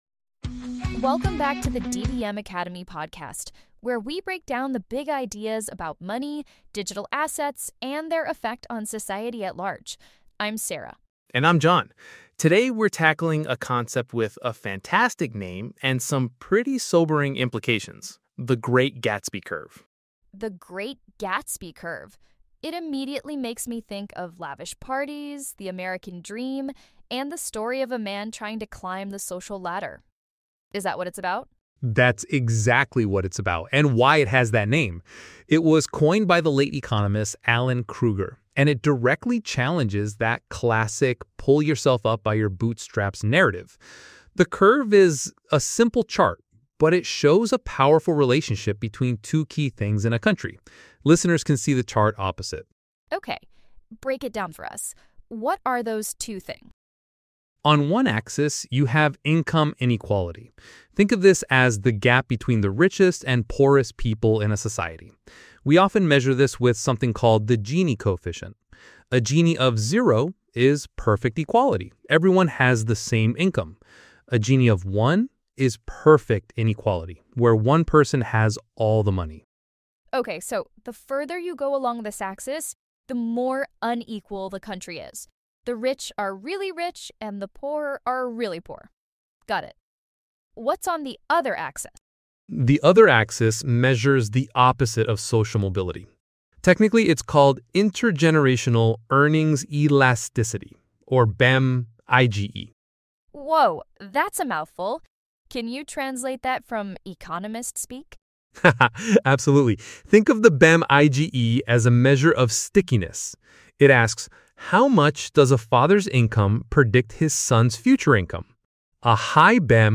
The hosts conclude by discussing how individuals can leverage education and "monetary energy" to disrupt these societal trends.